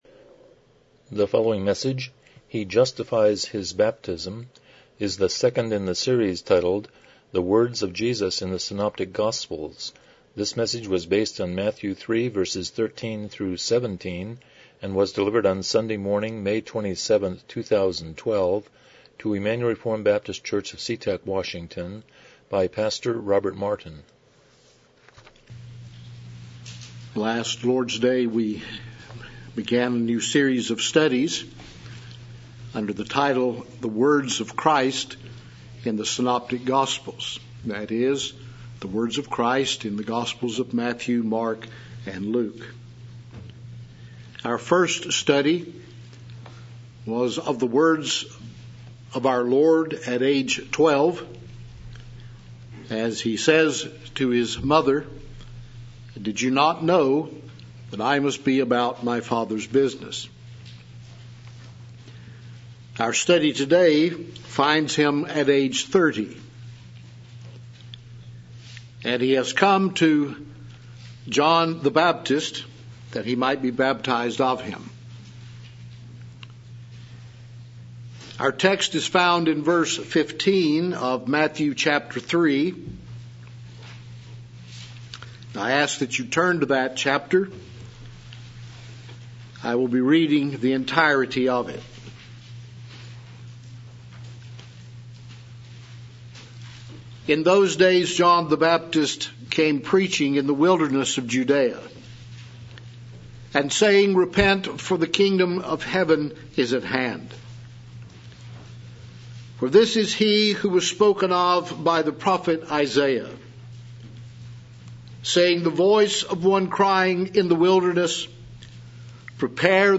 Passage: Matthew 3:13-17 Service Type: Morning Worship